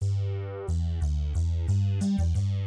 描述：用果味循环制作的Stringy Buzzing低音线。
Tag: 90 bpm Chill Out Loops Bass Loops 459.55 KB wav Key : G